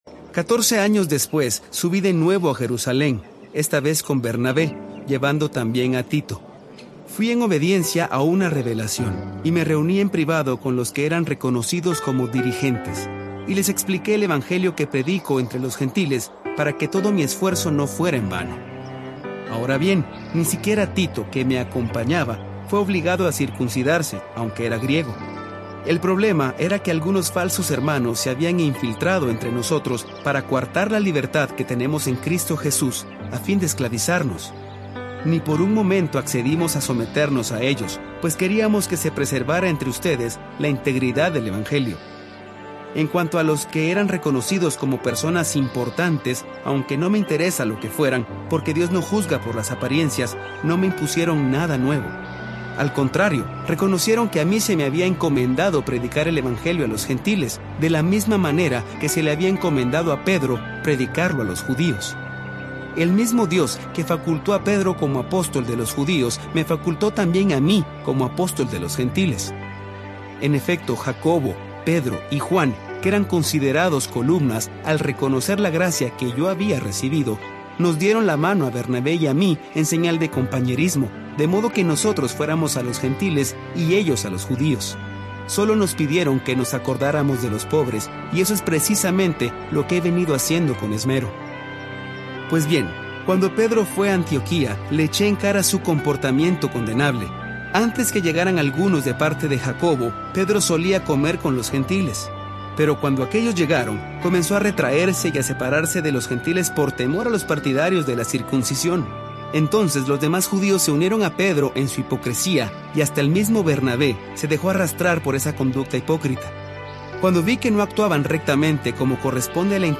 Disfruta Galátas-Colosenses en las voces de siervos, adoradores, pastores y maestros de la Biblia cuyos ministerios y acciones han bendecido a la Iglesia del Señor de habla hispana.